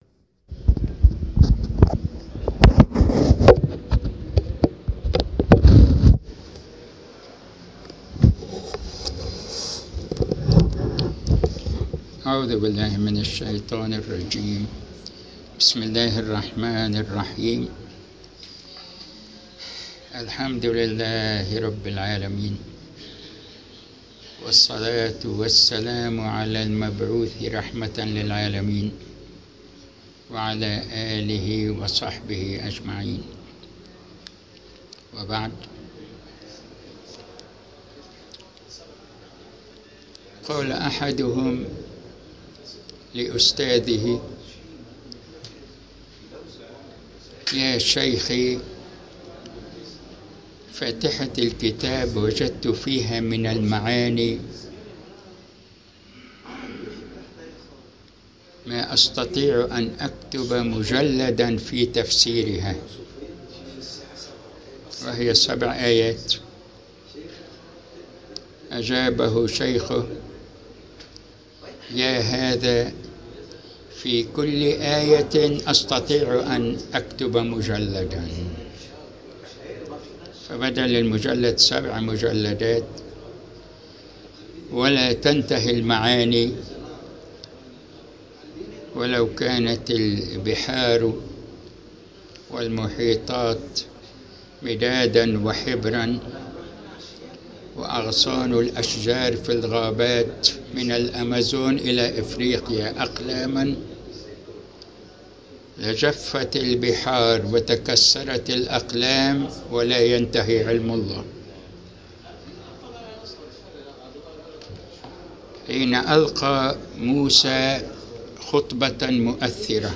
المكان : المسجد البحري الموضوع : واو العطف